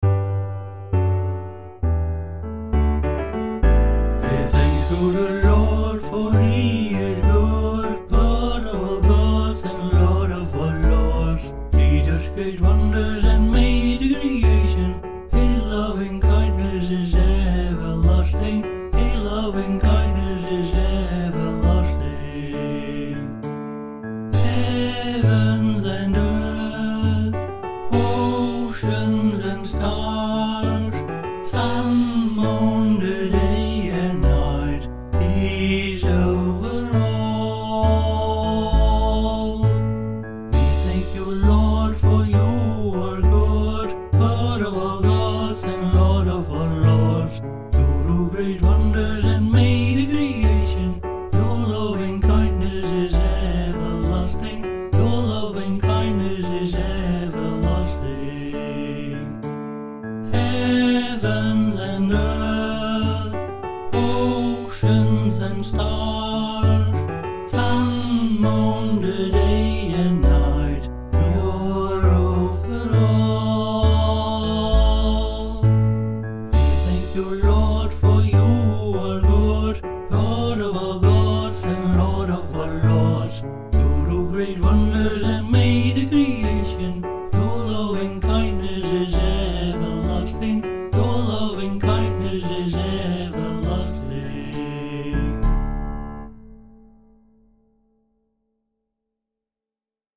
a joyful song of thanksgiving